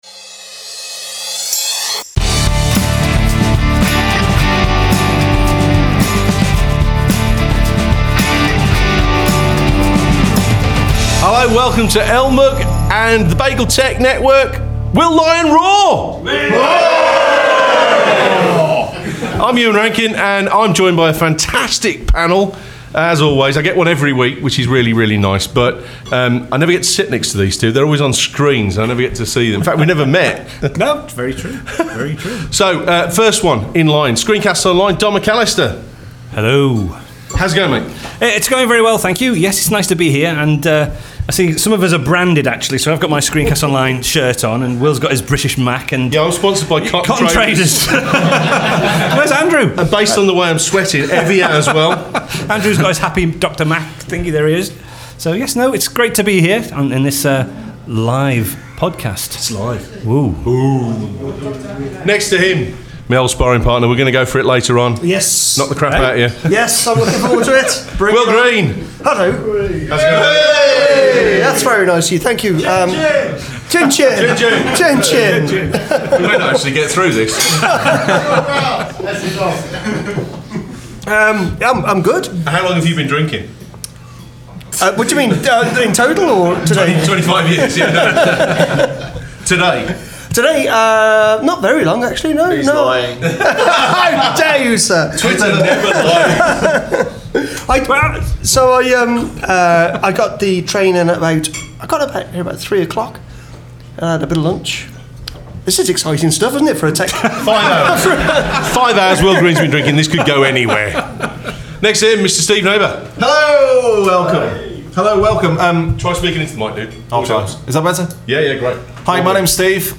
A very special event at the London Mac User Group and some great conversations